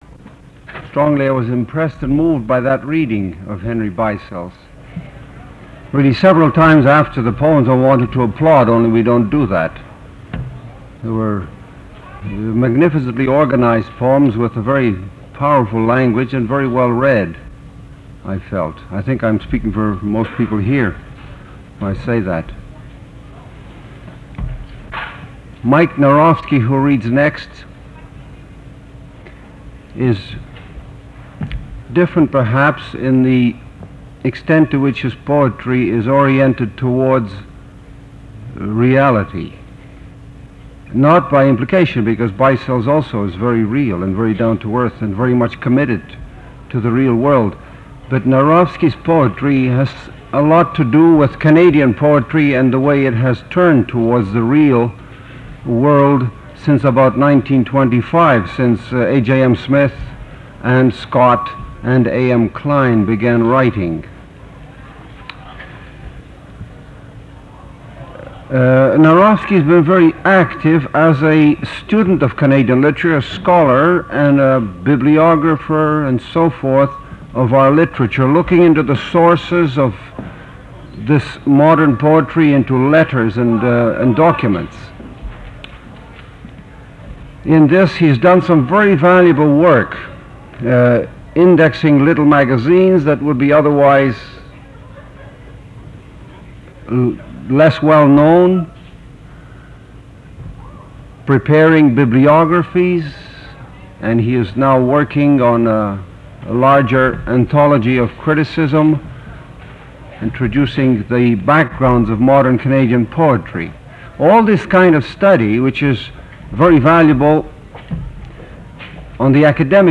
Series Title: The Poetry Series
Production Context: Documentary recording